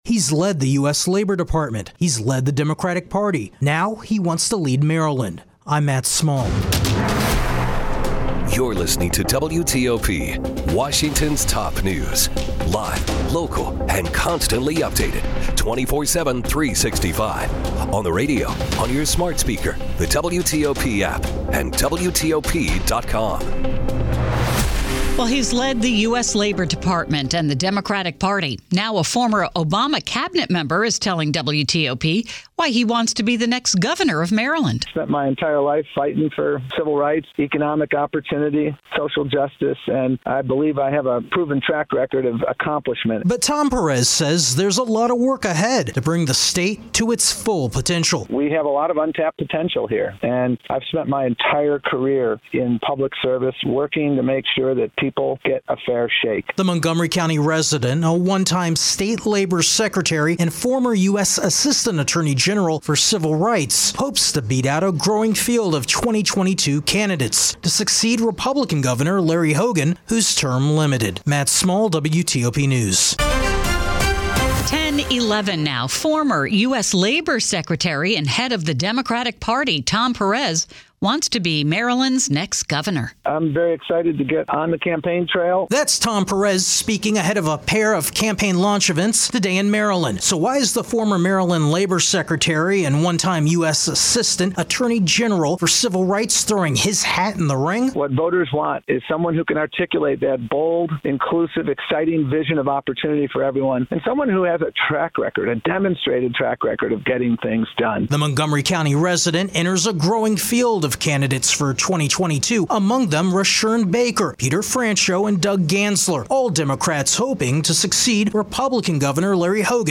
Tom Perez talks with WTOP about his quest to govern Maryland